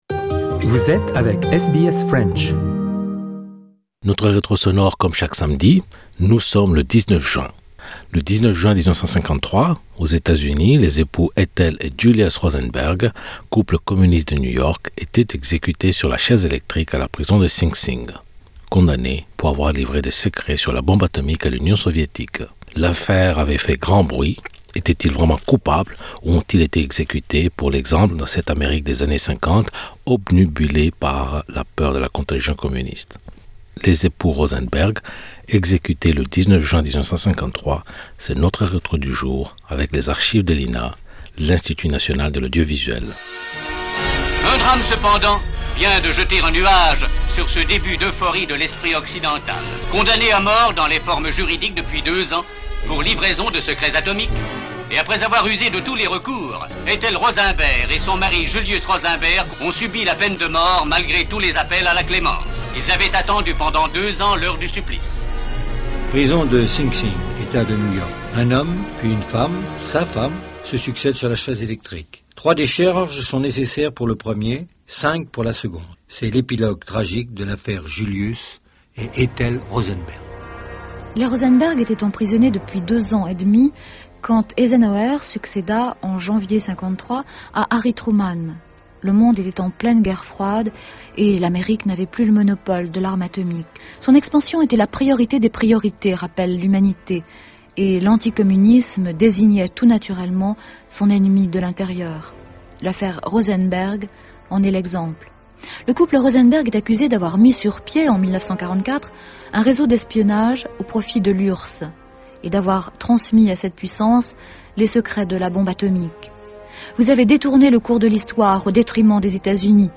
Les époux Rosenberg exécutés le 19 juin 1953, c'est notre retro du jour avec les archives de l'INA...l'Institut National de l'Audiovisuel